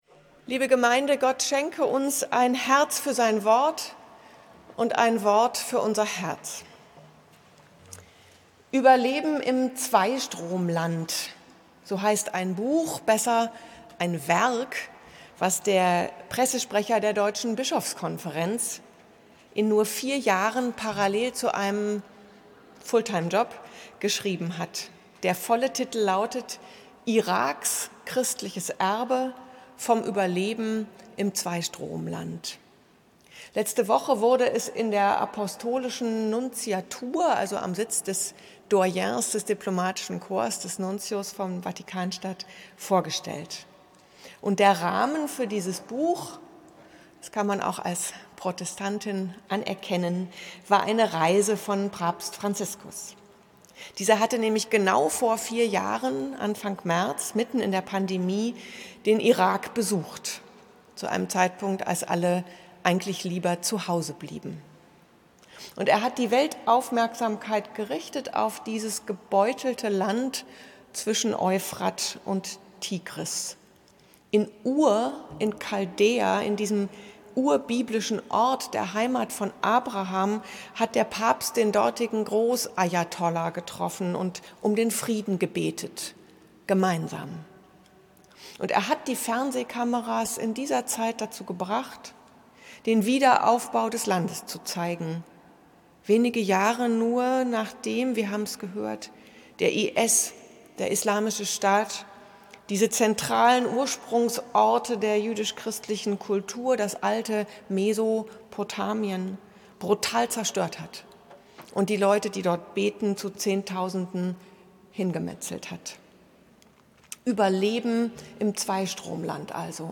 Die Predigt nachhören: Mediathek Berliner Dom